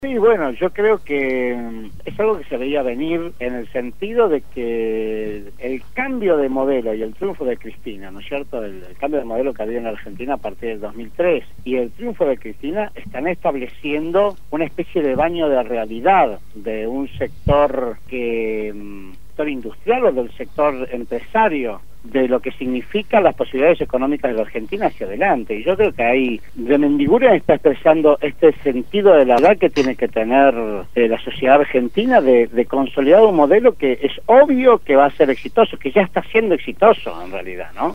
en el programa Punto de Partida de Radio Gráfica FM 89.3